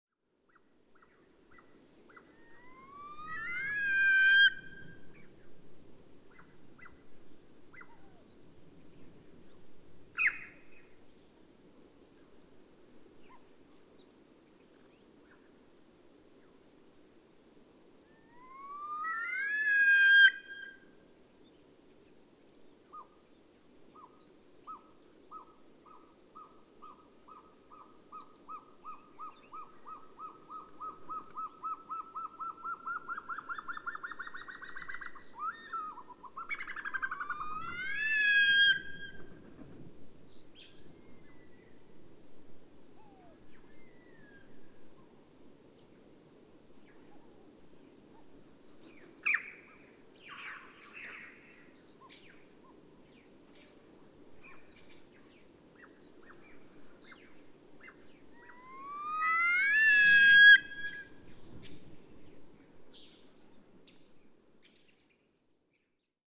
PFR09220-1, 130531, Altai Snow Cock, song, series of calls,
Bayankhongorijn Khukh Nuur, Mongolia